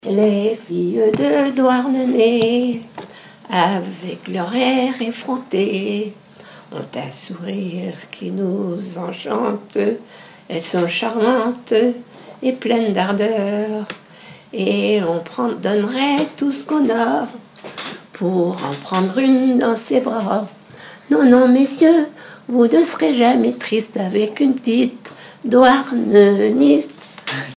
Catégorie Pièce musicale inédite